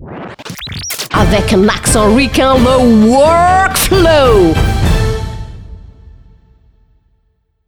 • Voix féminine / urbaine
SWEEP_-_LE_WORK_FLO_AMERICAIN.wav